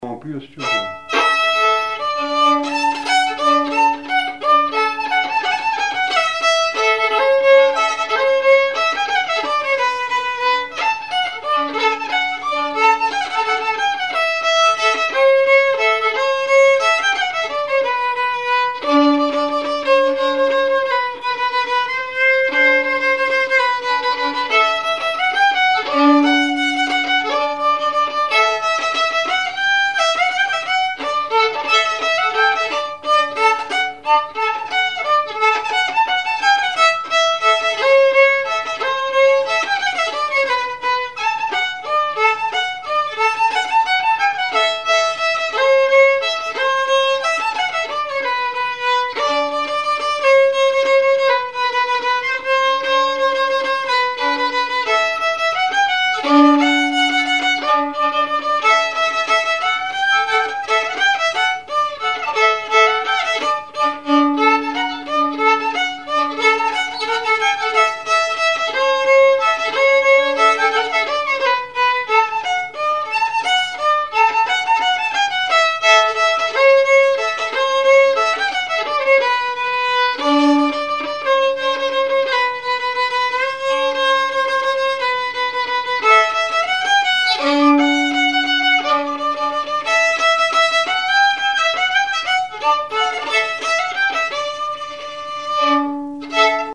danse : java
Témoignages et chansons
Pièce musicale inédite